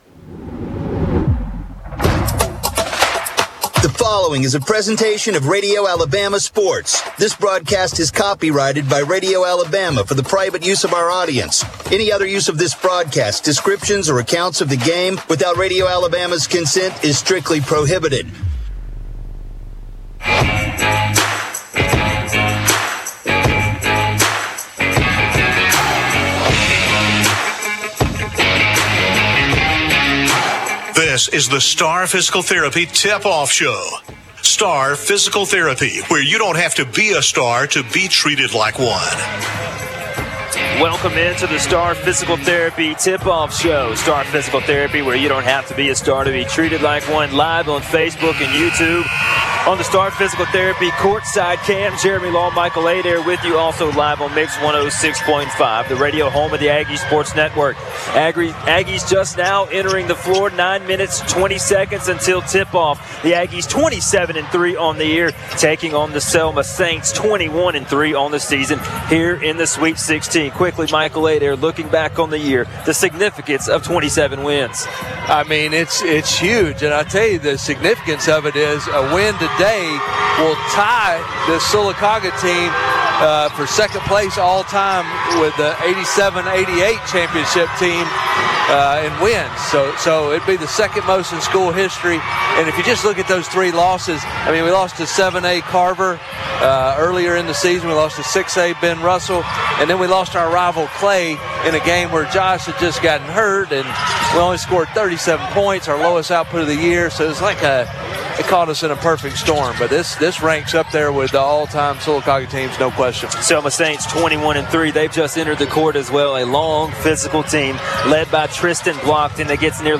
call Sylacauga's game against Selma in the State Tournament Sweet Sixteen. The Aggies won 51-50.